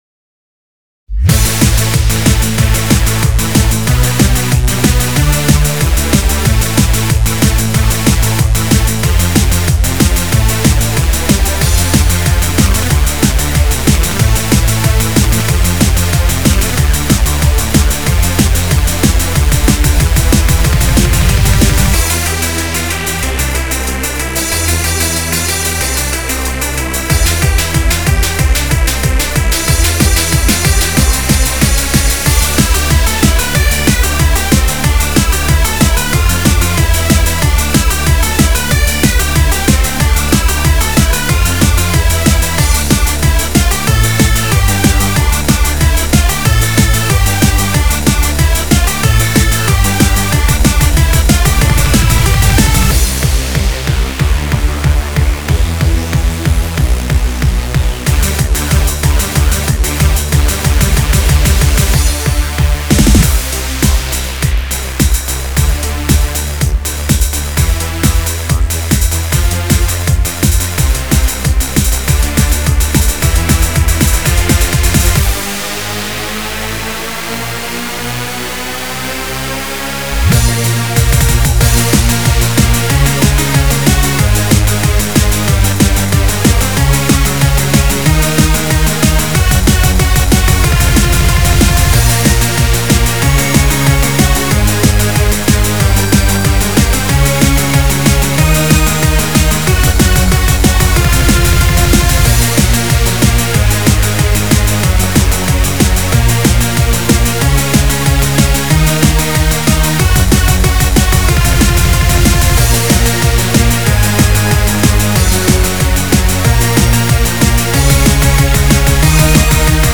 BPM186-372
Audio QualityPerfect (High Quality)
Genre: Freeform Hardcore